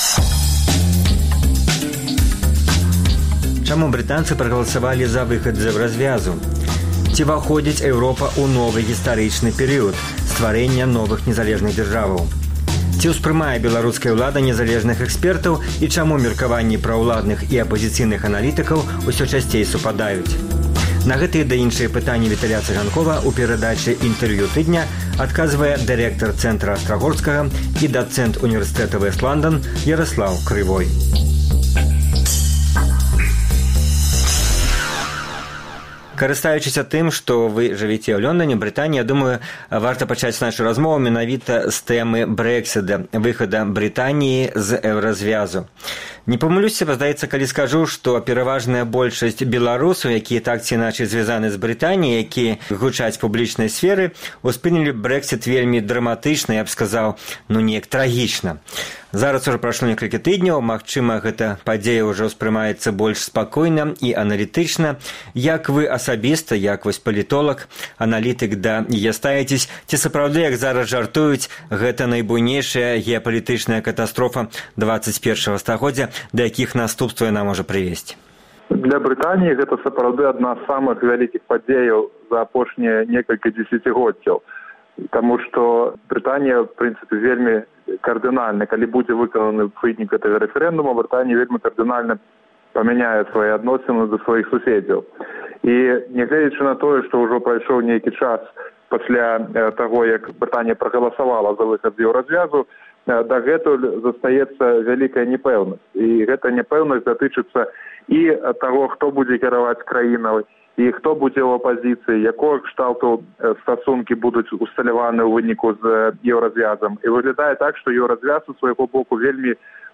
Інтэрвію тыдня